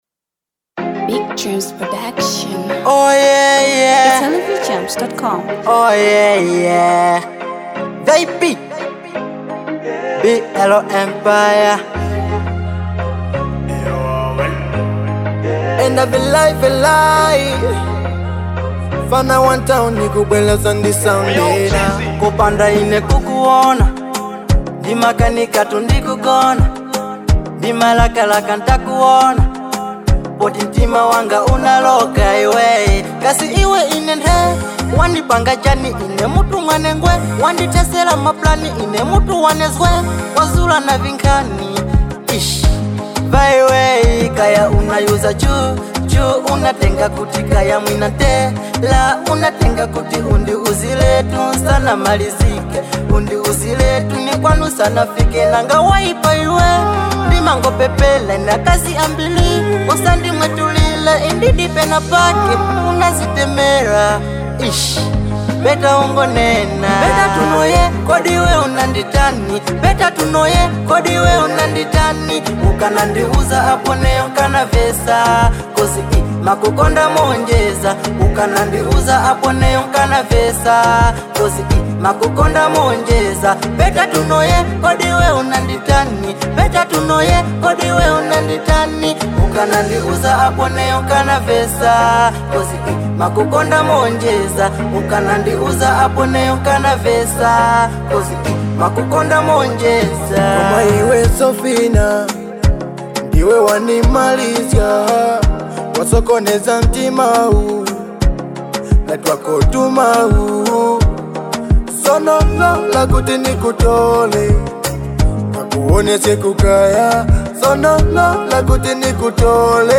Afro